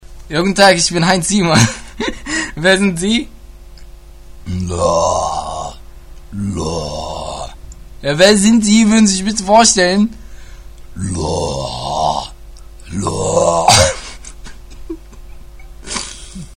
Interview
Heinz Sielmann interviewt gerade den Lohrbären.
Sielman-interview.mp3